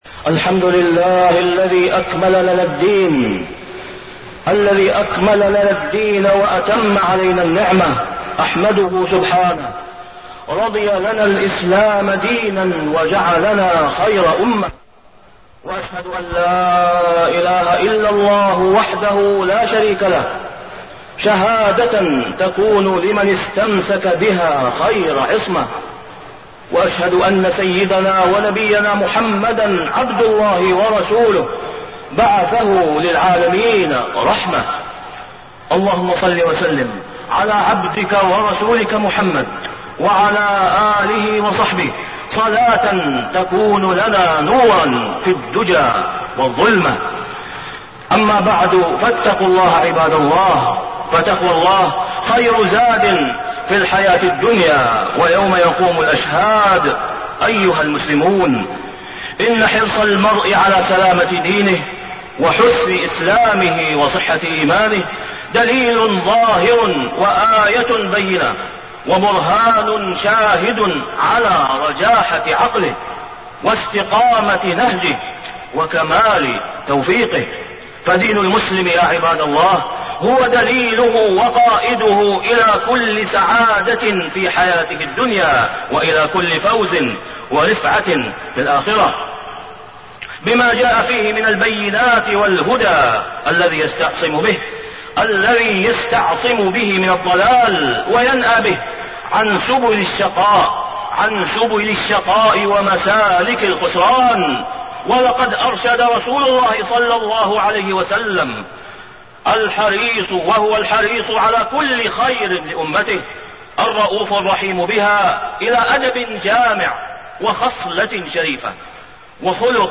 تاريخ النشر ٦ شوال ١٤٣٠ هـ المكان: المسجد الحرام الشيخ: فضيلة الشيخ د. أسامة بن عبدالله خياط فضيلة الشيخ د. أسامة بن عبدالله خياط من حسن إسلام المرء تركه ما لا يعنيه